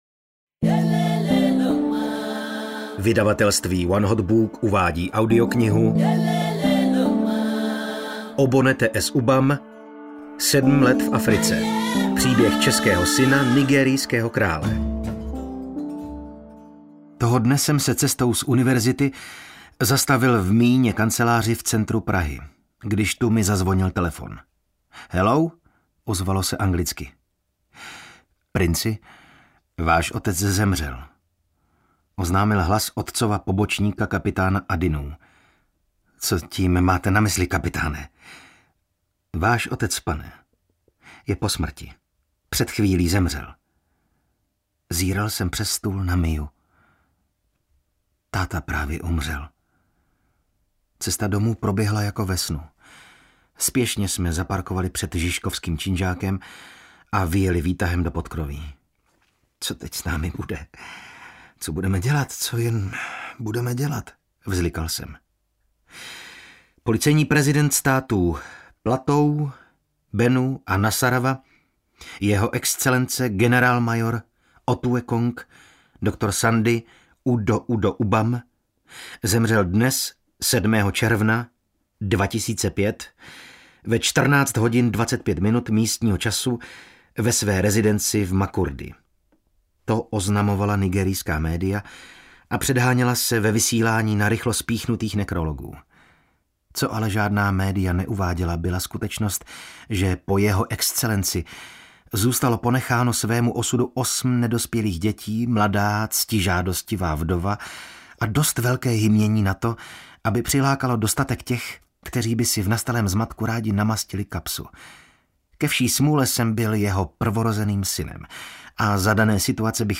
Sedm let v Africe audiokniha
Ukázka z knihy